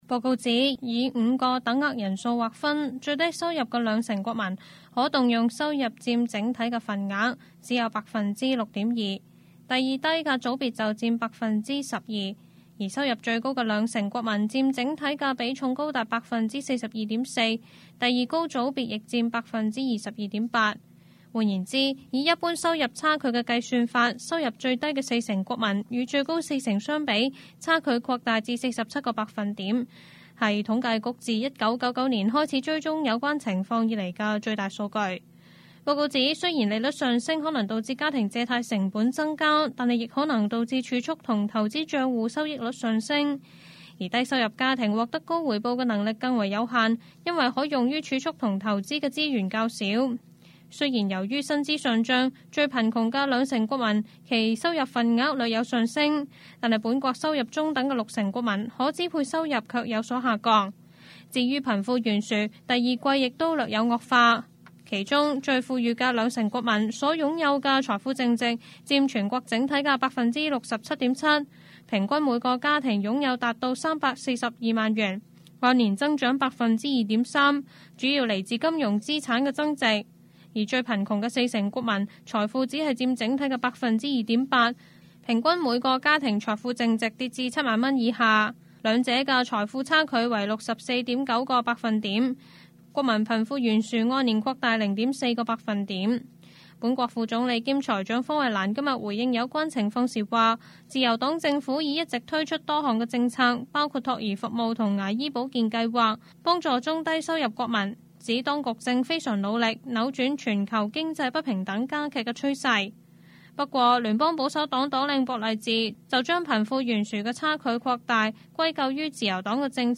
Canada/World News 全國/世界新聞
news_clip_20868.mp3